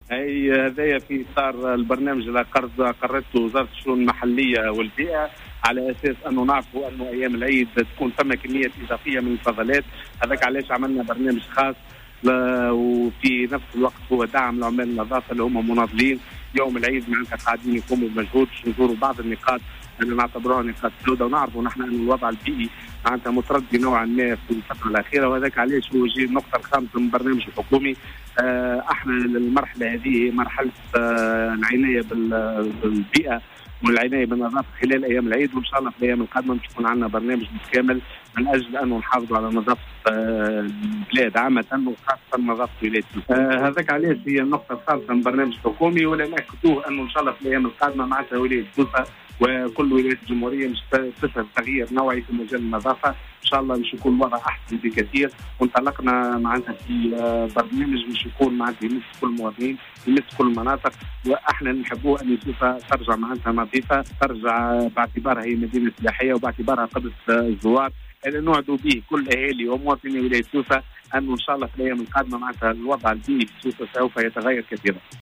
وقال في تصريح لـ "الجوهرة اف أم" إنه قام اليوم بزيارة بعض النقاط في سوسة في إطار برنامج تم إقراره للتصدي لتردي الوضع البيئي بمدينة سوسة وباقي المدن التونسية.